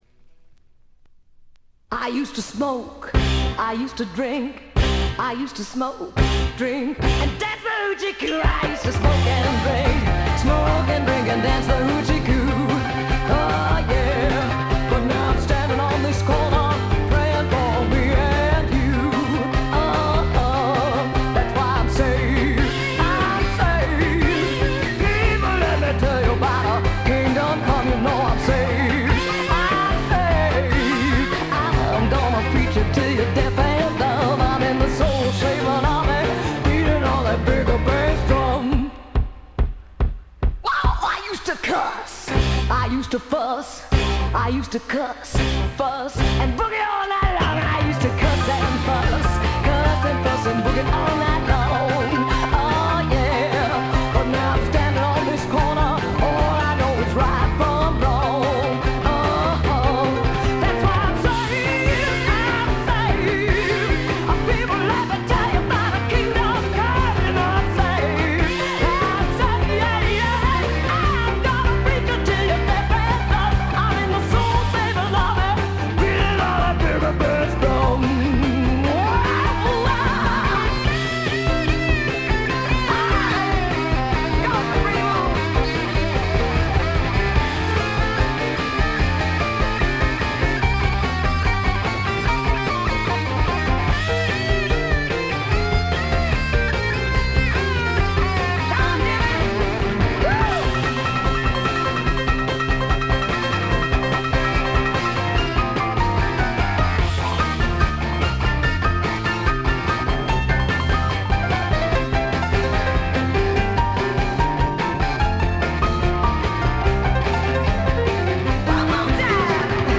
これ以外はわずかなノイズ感のみ。
前作同様ハード・スワンプ、ハード・ファンクの名盤。
試聴曲は現品からの取り込み音源です。